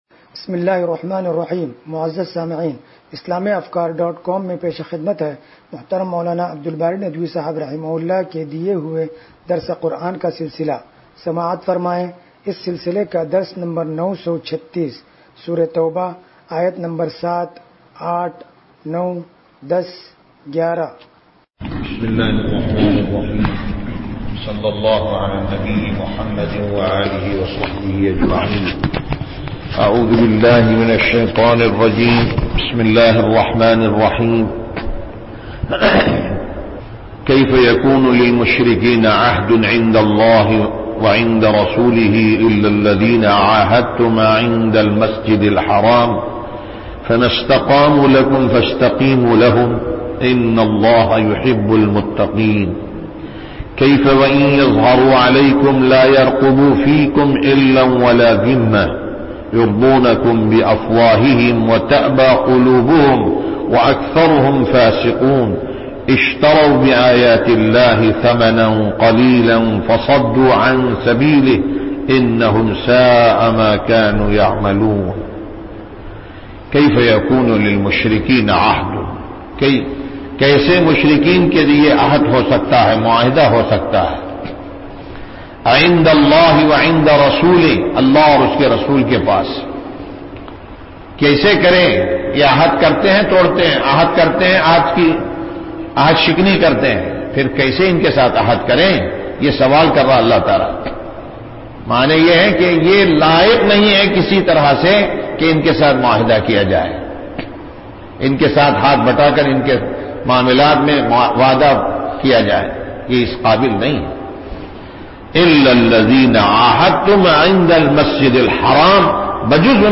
درس قرآن نمبر 0936